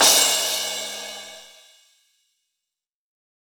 YCRASH 1.wav